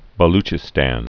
(bə-lchĭ-stăn, -stän) or Ba·lo·chi·stan (-lō-)